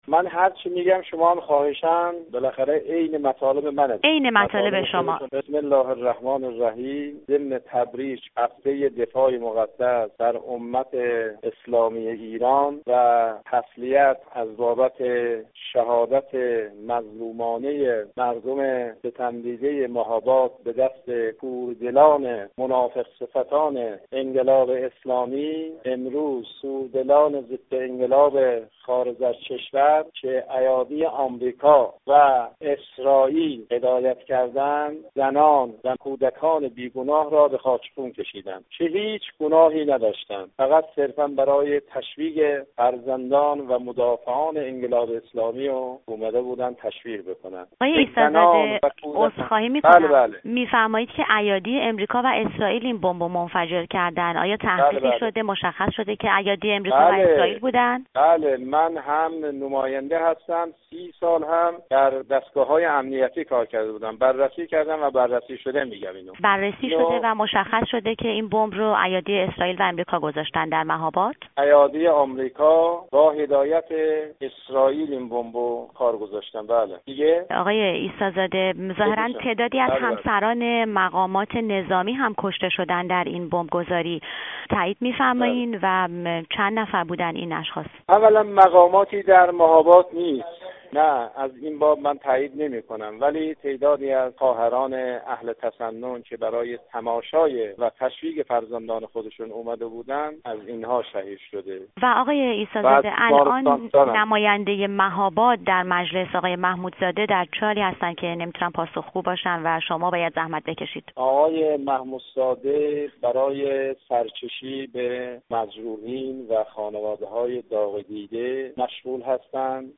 گفت‌وگوی
نماینده کرد در مجلس شورای اسلامی